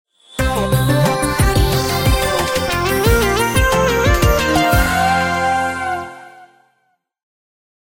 • Качество: 128, Stereo
веселые
без слов
электронные
Музыка из игры